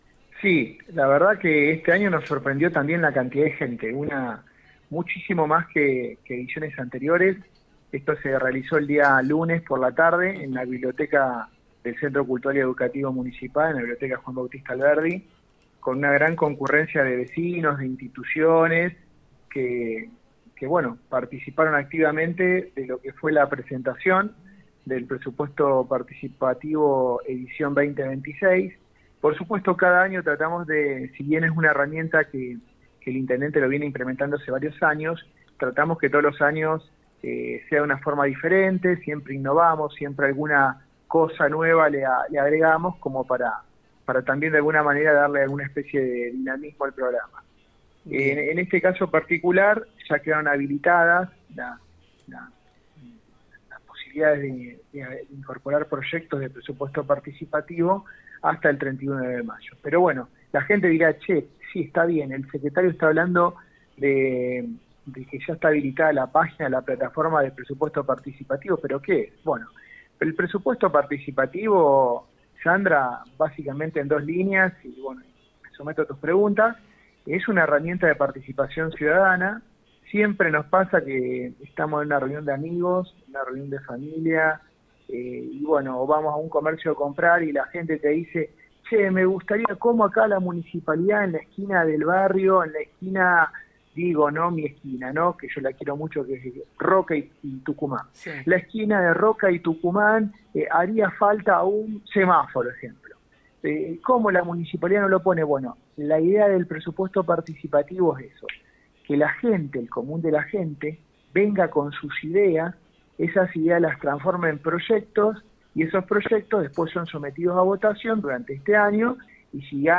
En ese marco, el secretario de Coordinación General, Alejandro Cabral, explicó en diálogo con el programa «Con Voz» por FM 102.9 los detalles sobre la presentación de iniciativas y los pasos que deben seguir los vecinos para participar.